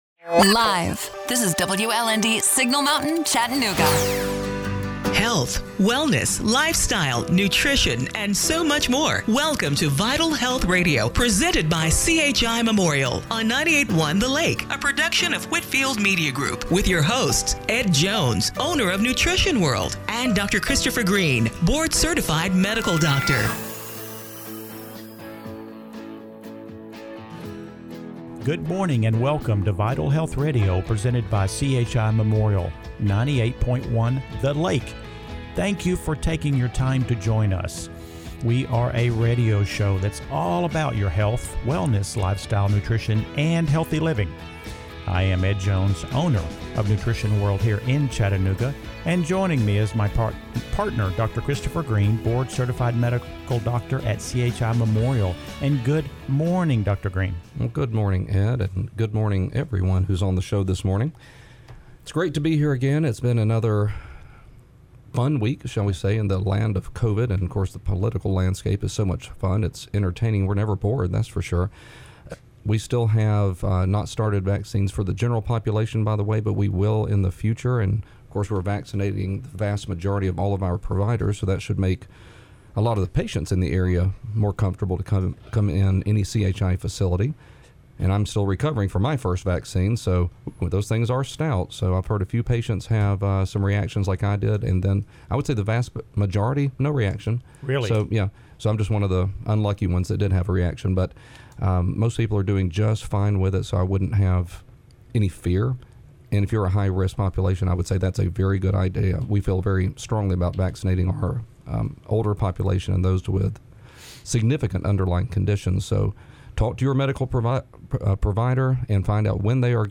January 17, 2021 – Radio Show - Vital Health Radio